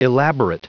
, elaborative.adjectives.(pronounced 'e lab or it')